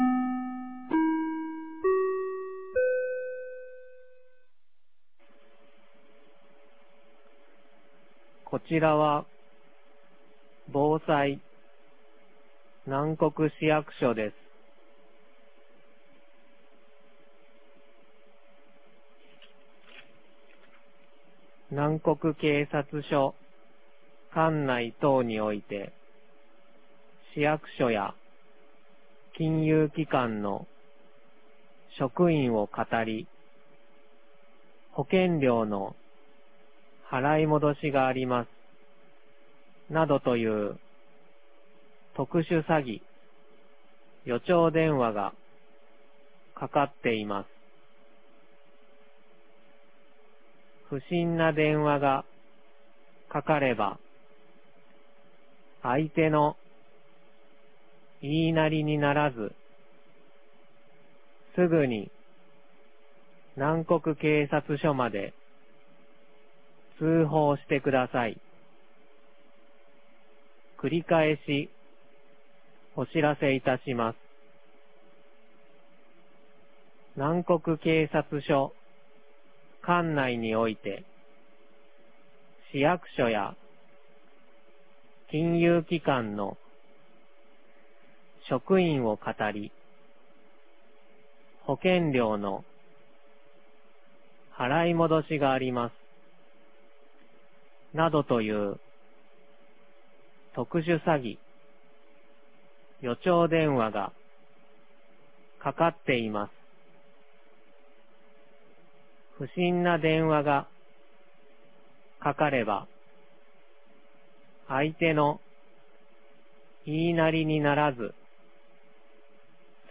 2022年10月12日 17時44分に、南国市より放送がありました。